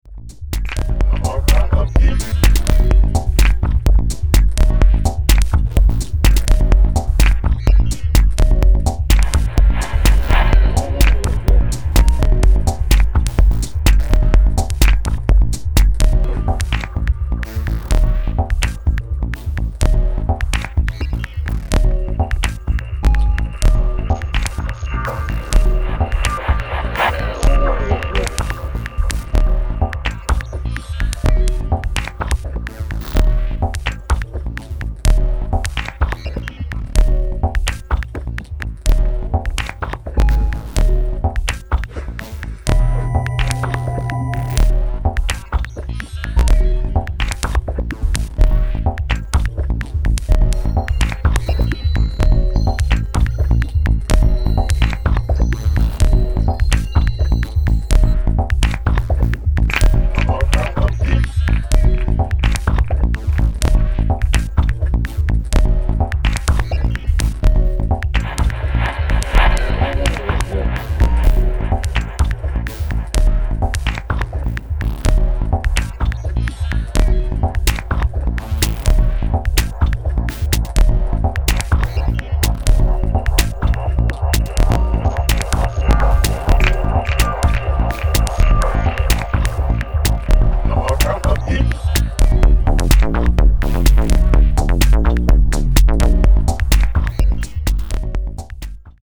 妖艶に蠢くアヴァンギャルドな音像、あくまでもファンキーでしなやかなハウスビート。